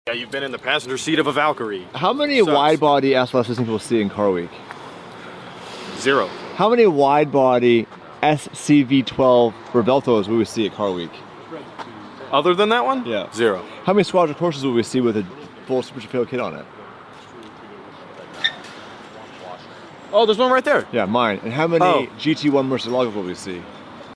Car Week_ Widebody SLS, SCV12, sound effects free download